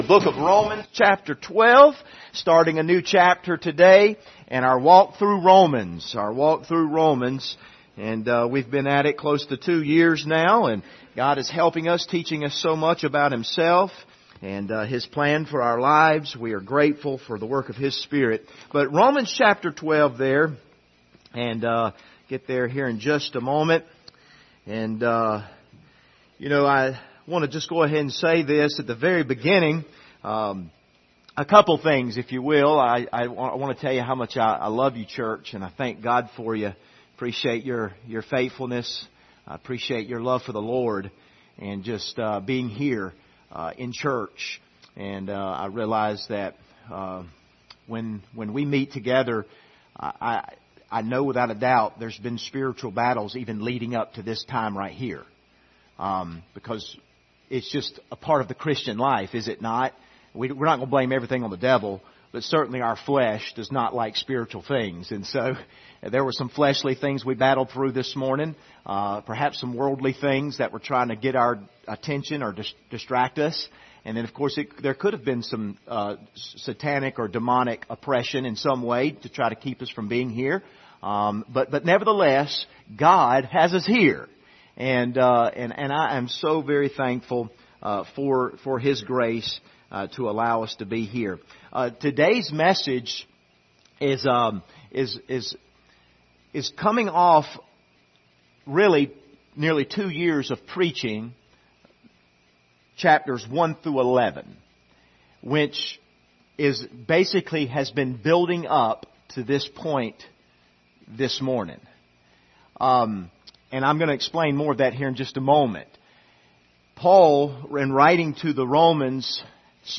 Passage: Romans 12:1 Service Type: Sunday Morning